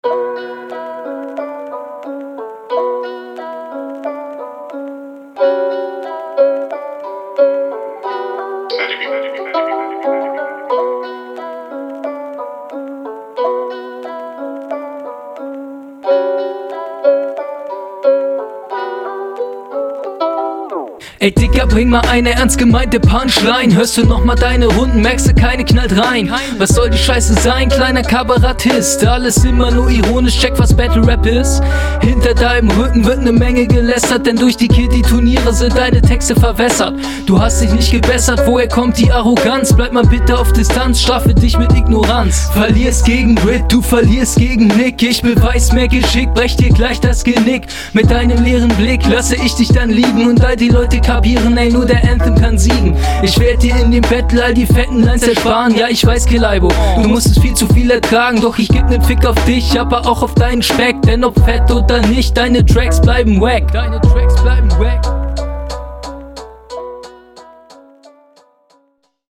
Absolut stabil gerappt, Stimme und Beat passen gut zusammen, da gibt es nichts zu meckern.